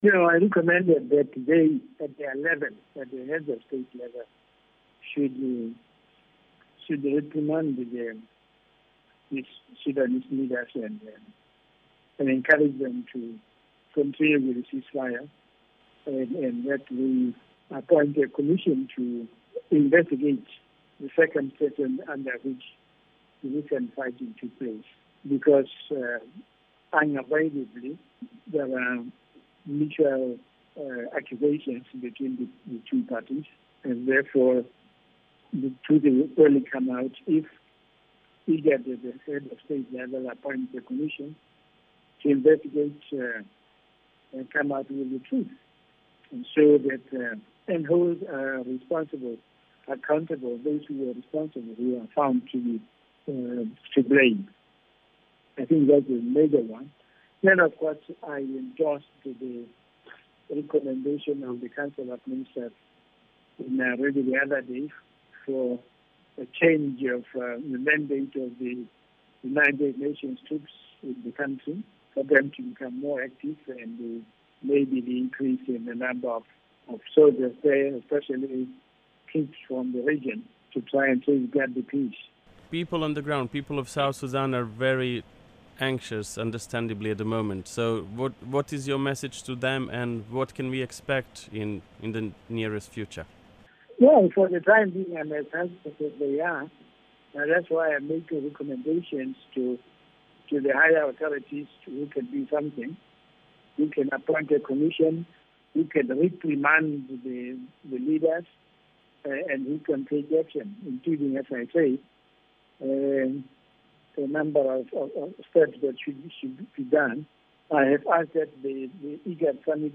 In an exclusive interview with Radio Miraya, Mr Mogae called on the IGAD to assure the people of South Sudan of their continuing commitment to the peace process